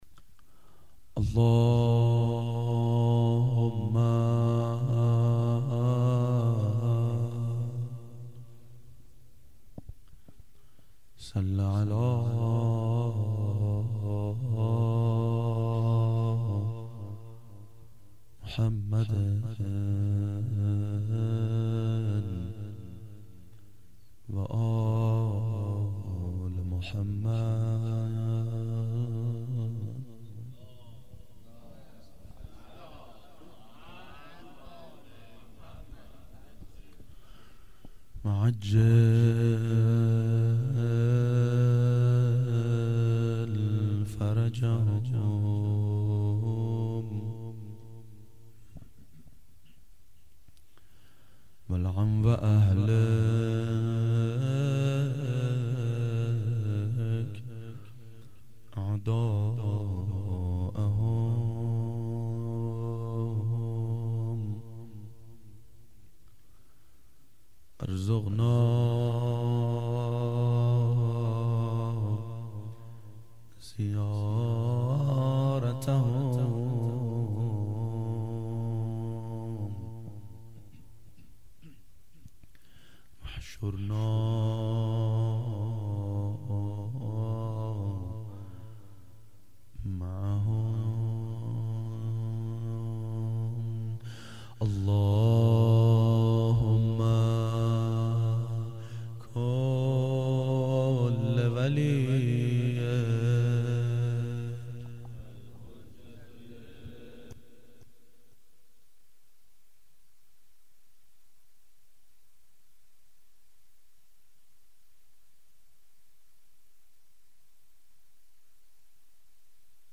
مناجات با امام زمان علیه السلام و روضه
02-monajat-ba-emame-zaman-roze-hazrate-zahra.mp3